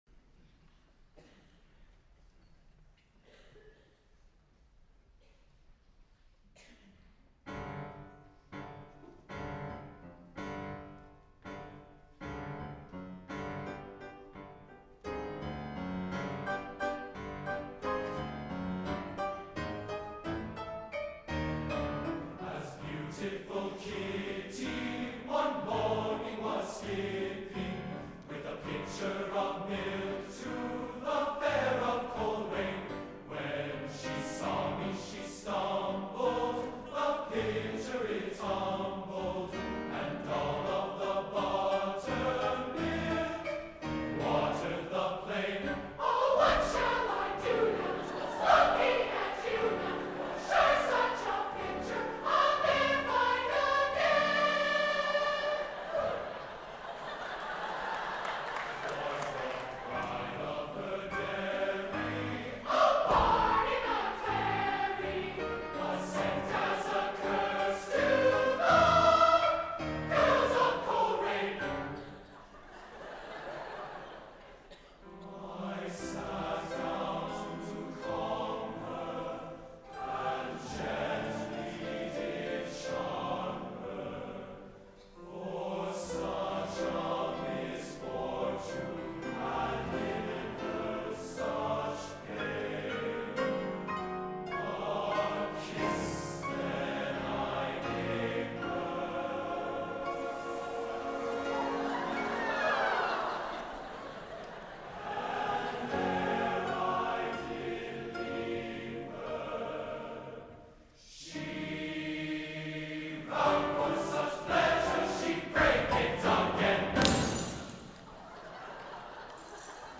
Accompaniment:      With Piano
Music Category:      World